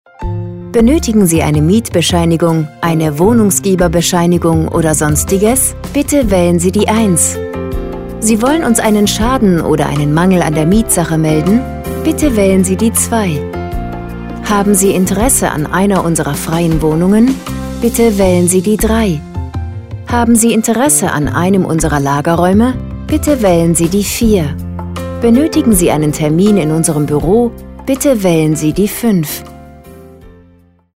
IVR Ansage
2-IVR-Ansage.mp3